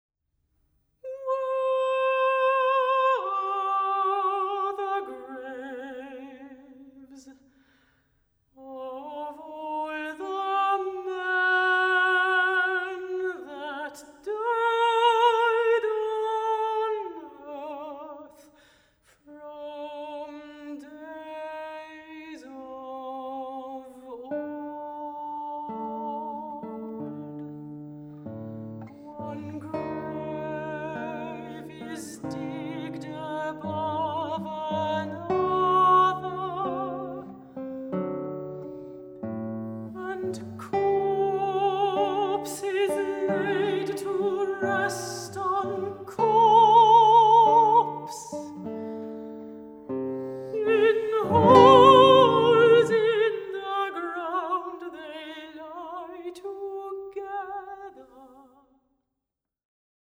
Gitarre
Gesang